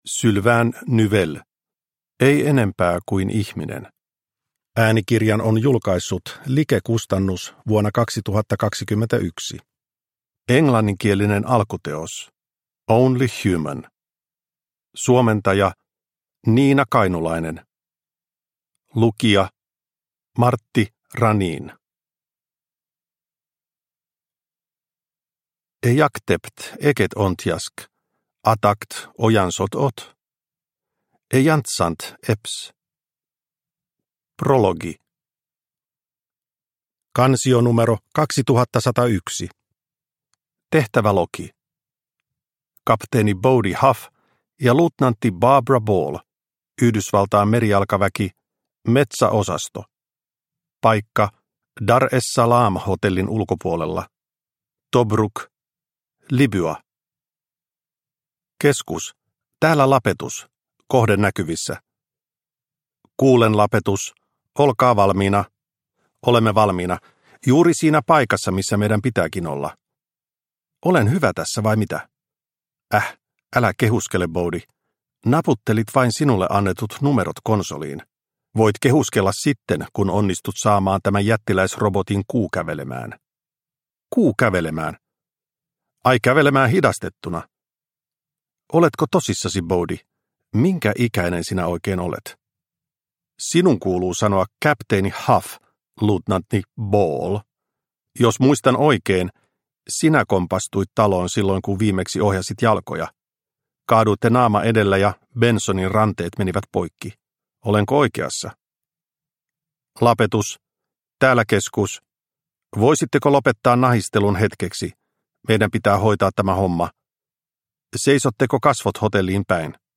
Ei enempää kuin ihminen – Ljudbok – Laddas ner